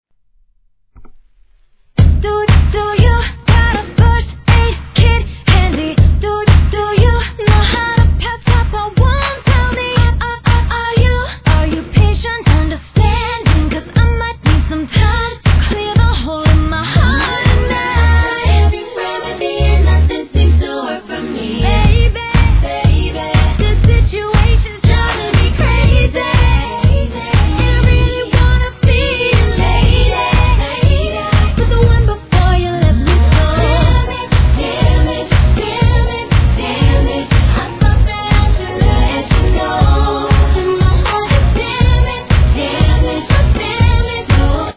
HIP HOP/R&B
美女5人組のセレブお姉さん方のNEWはBPM速めの踊れるダンスナンバーとなっております！